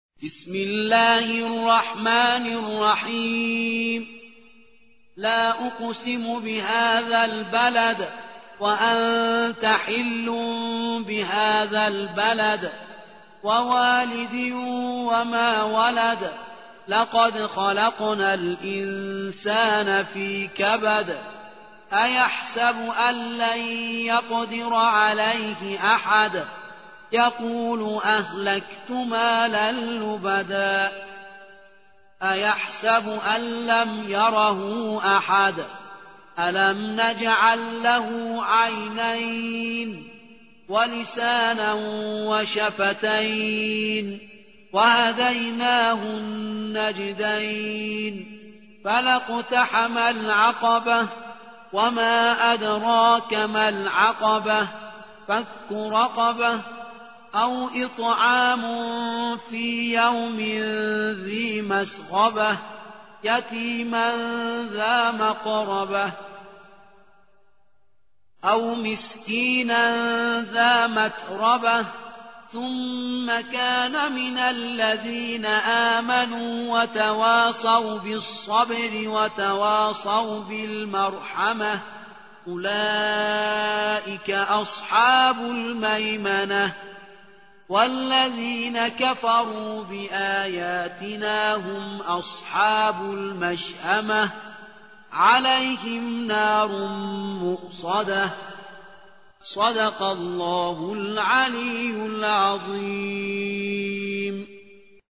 90. سورة البلد / القارئ